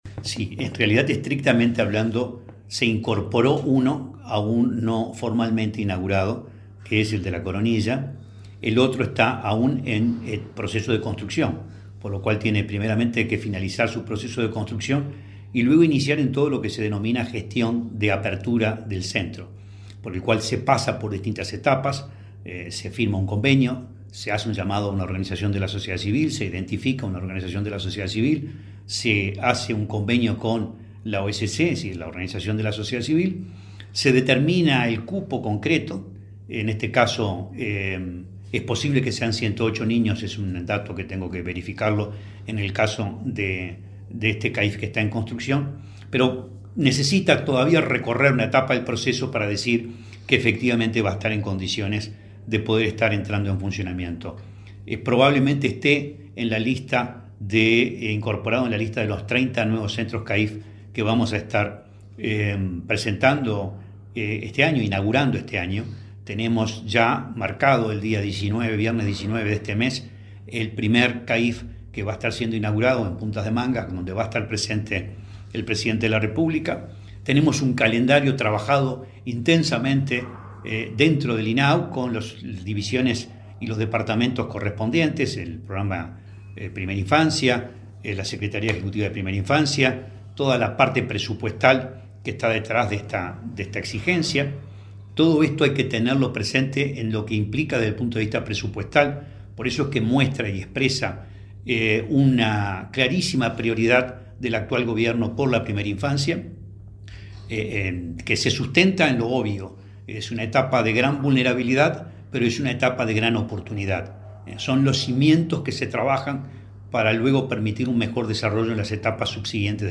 Entrevista al presidente de INAU, Guillermo Fosatti